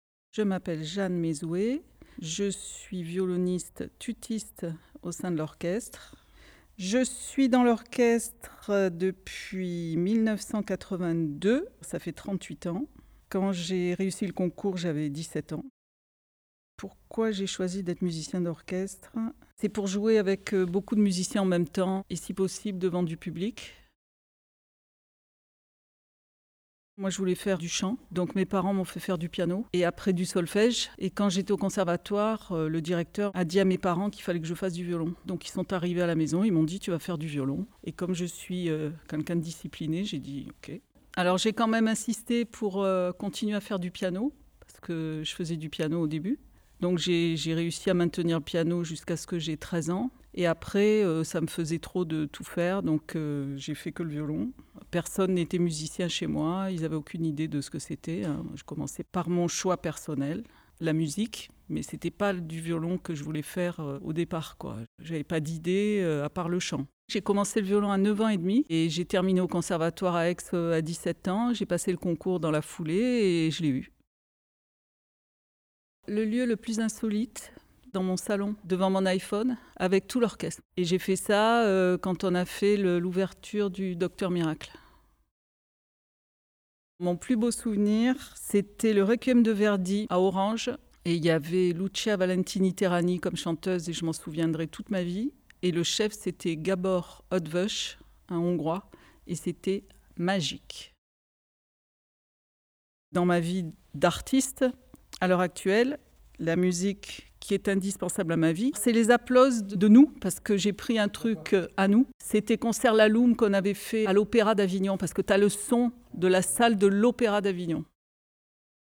Portrait sonore :
Ils ont accepté de répondre pour vous à ces questions.